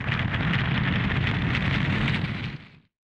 foundry-rocks.ogg